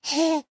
giggletoddler.ogg